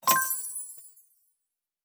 Special & Powerup (55).wav